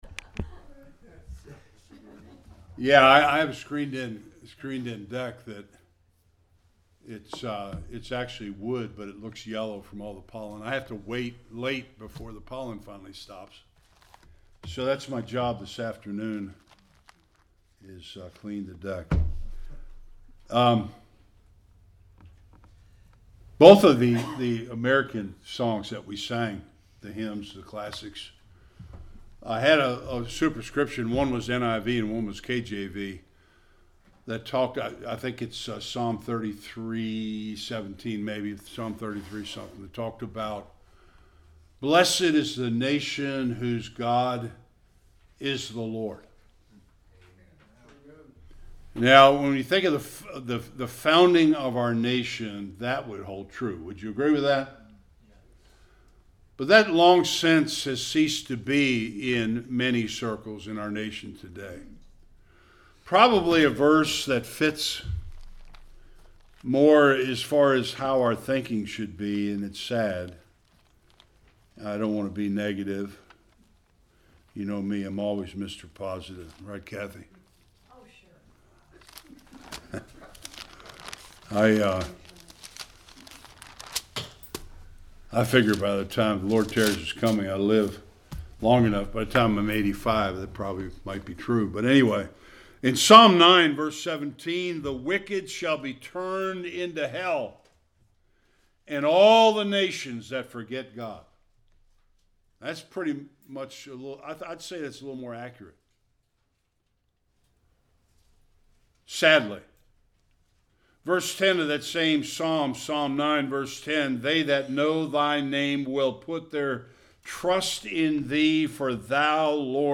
Various Passages Service Type: Sunday School Many have died to defend our freedom.